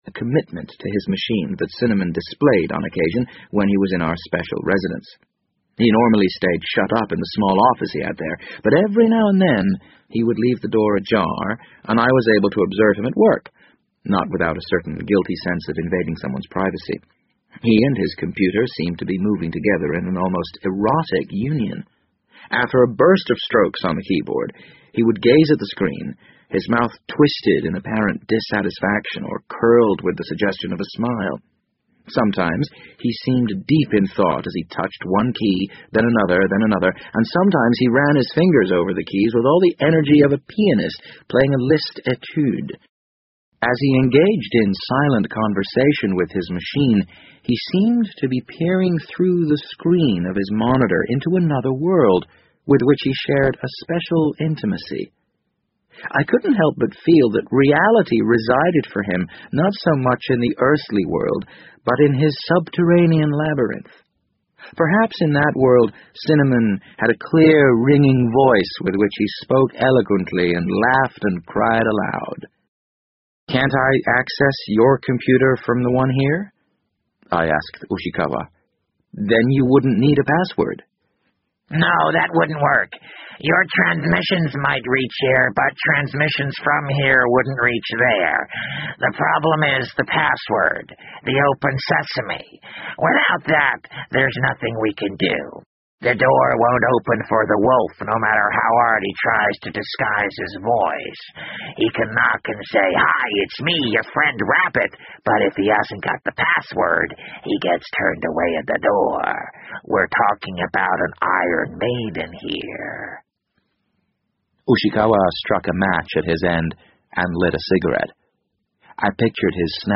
BBC英文广播剧在线听 The Wind Up Bird 012 - 7 听力文件下载—在线英语听力室